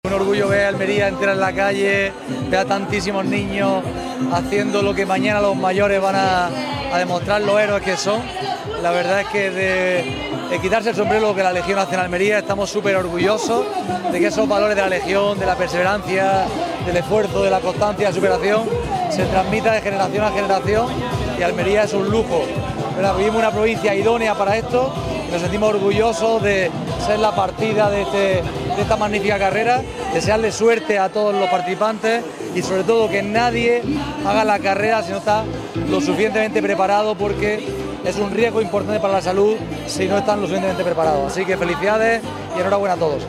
ANTONIO-JESUS-CASIMIRO-CONCEJAL-CIUDAD-ACTIVA-MINIDESERTICA.mp3